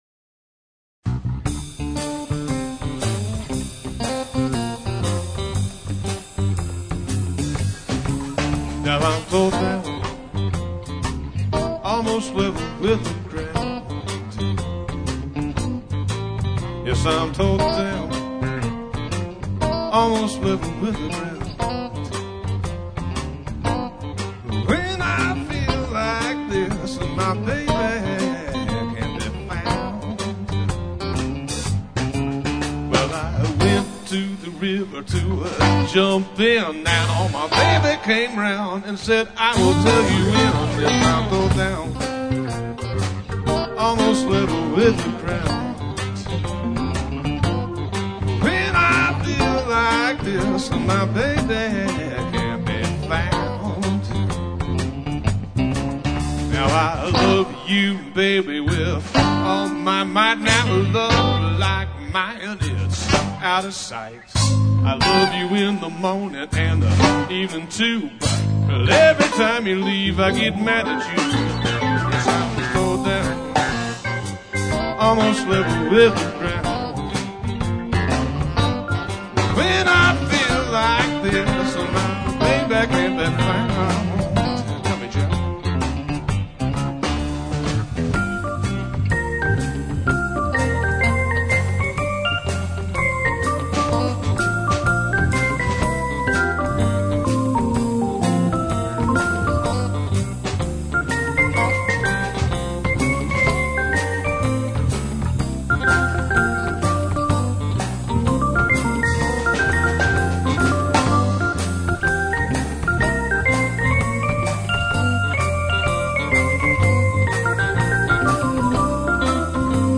Songs Archive from the Defunct Grass Flats Blues Jam